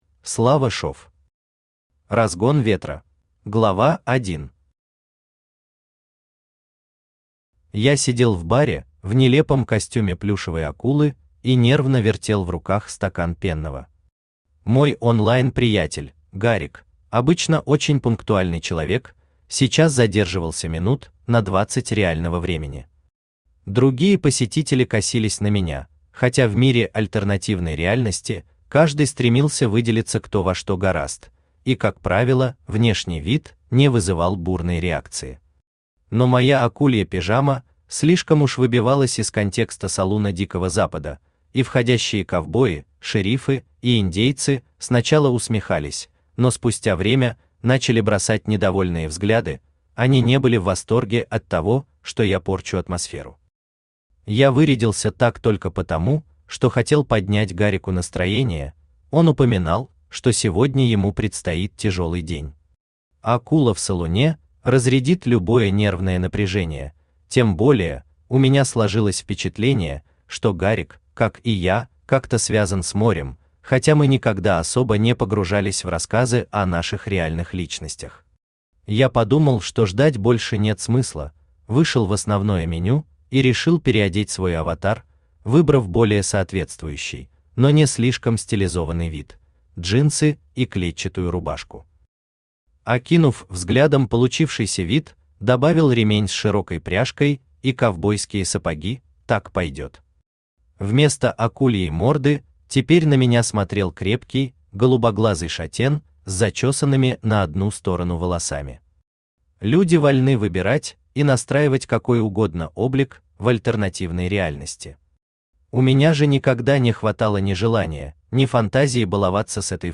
Аудиокнига Разгон ветра | Библиотека аудиокниг
Aудиокнига Разгон ветра Автор Слава Шев Читает аудиокнигу Авточтец ЛитРес.